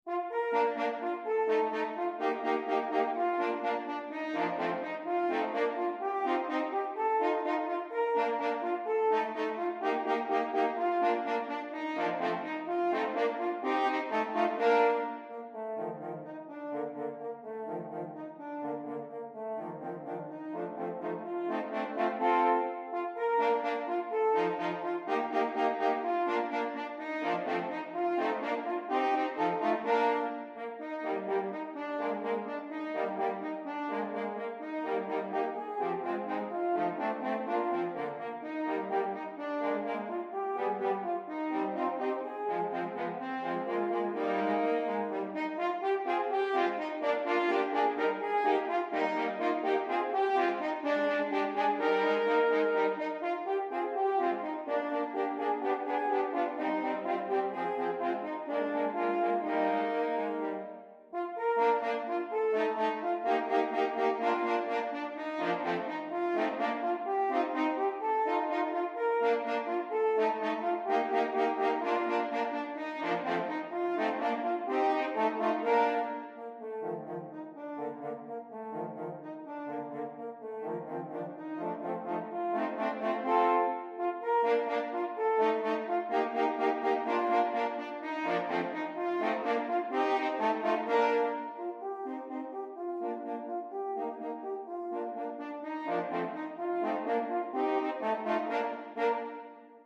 set for 3 like-instruments